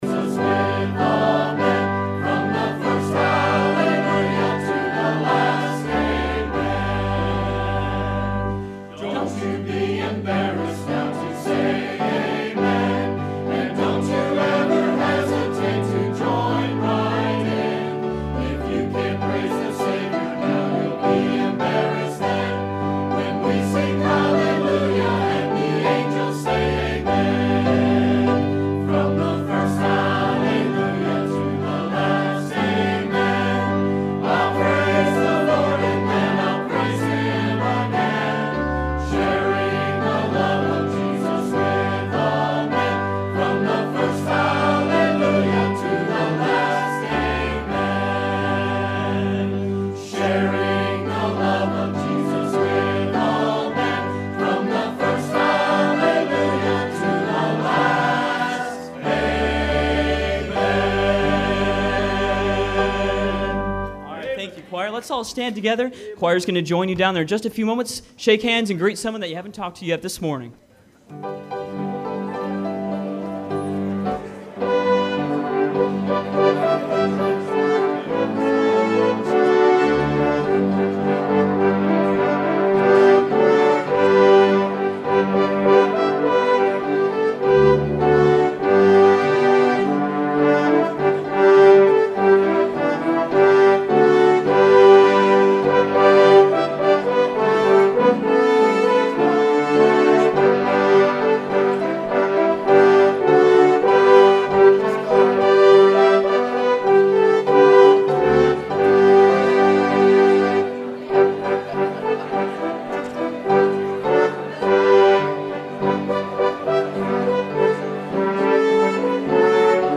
preaches from Acts 23:11-16. We don’t know what will happen tomorrow however we can trust God who is in control. God’s providential care takes care of His people as He did for Paul.